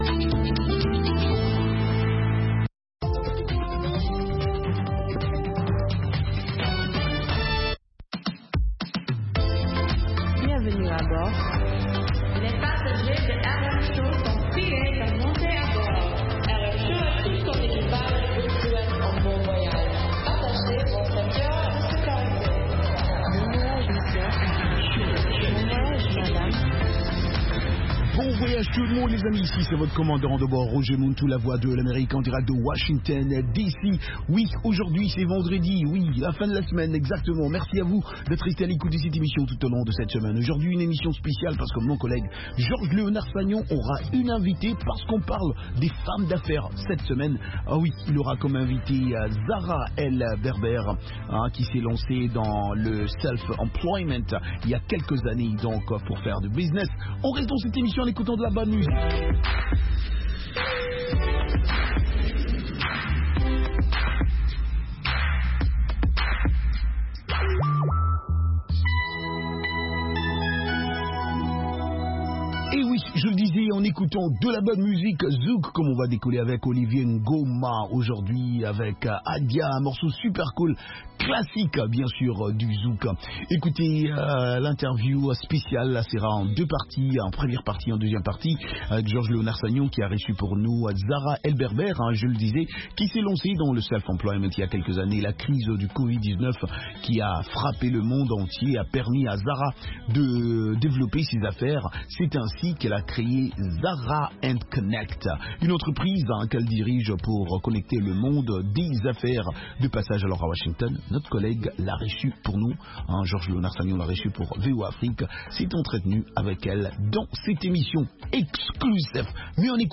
Ecoutez toute la musique des îles, Zouk, Reggae, Latino, Soca, Compas et Afro